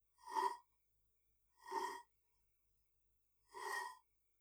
scrape3.wav